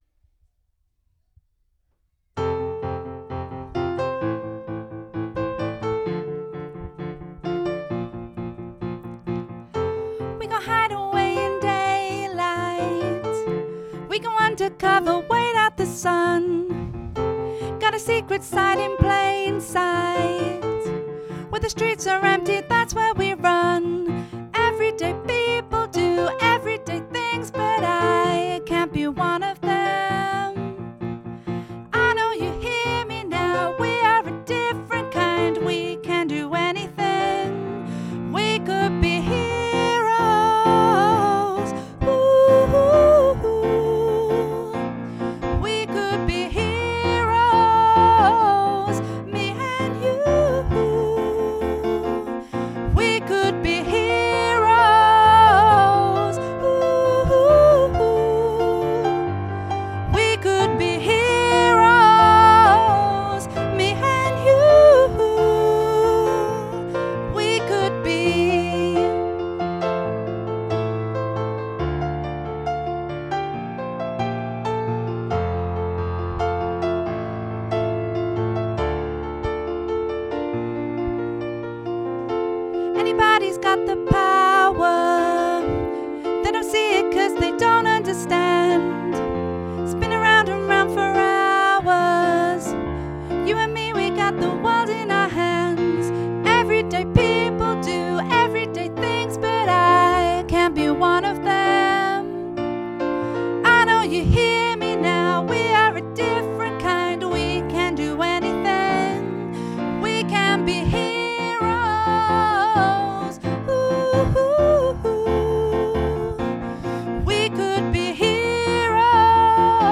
Performance Track